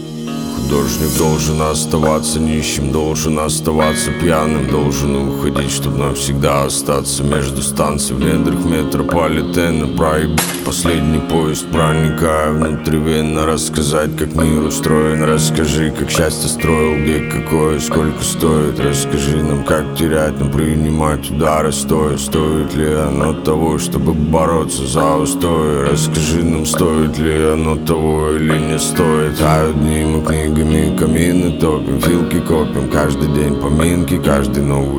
Hip-Hop in Russian Hip-Hop Rap